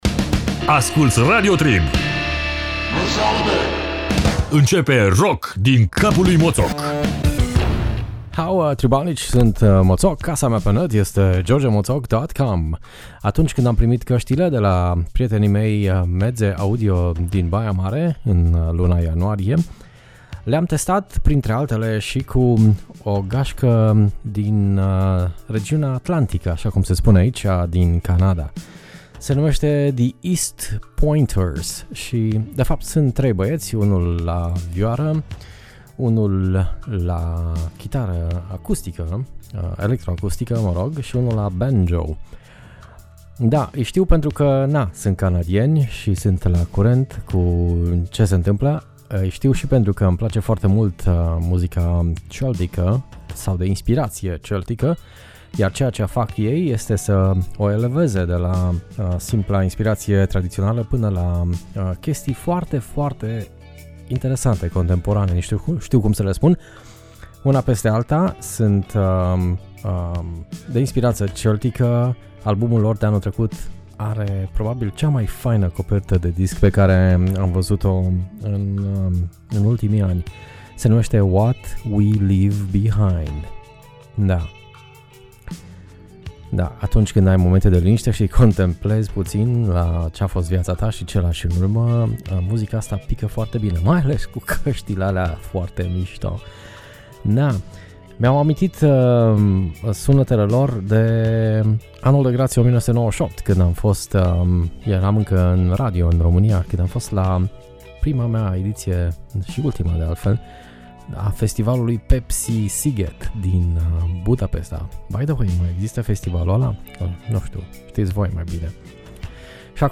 Muzică din triburile canadiene de pe malul Atlanticului.